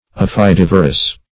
Search Result for " aphidivorous" : The Collaborative International Dictionary of English v.0.48: Aphidivorous \Aph`i*div"o*rous\ [Aphis + L. vorare to devour.]
aphidivorous.mp3